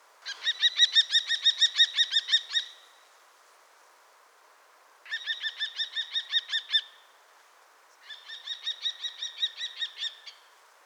Turmfalke Ruf
In der Nähe des Nestes hört man oft das typische „kik-kik-kik“, ein Laut zur Kommunikation und zur Revierverteidigung.
Der-Turmfalke-Ruf-Voegel-in-Europa.wav